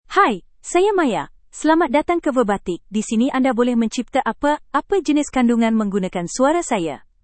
Maya — Female Malayalam (India) AI Voice | TTS, Voice Cloning & Video | Verbatik AI
Maya is a female AI voice for Malayalam (India).
Voice sample
Listen to Maya's female Malayalam voice.
Maya delivers clear pronunciation with authentic India Malayalam intonation, making your content sound professionally produced.